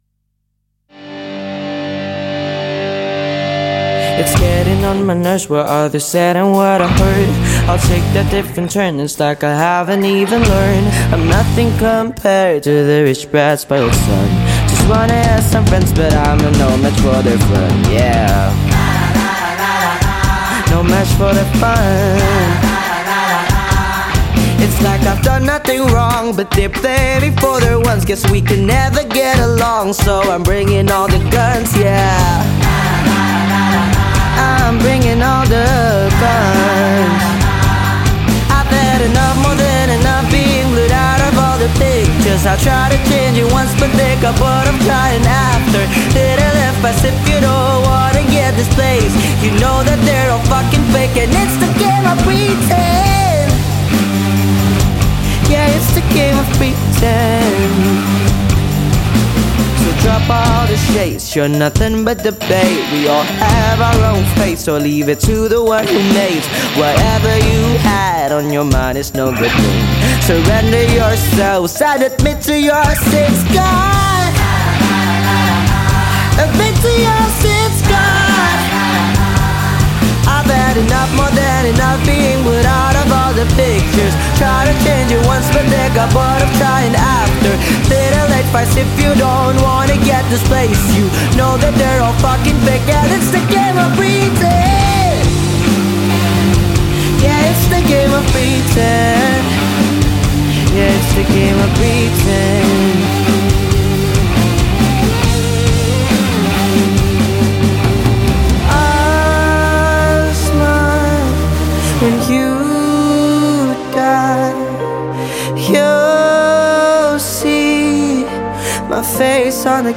Surabaya Alternative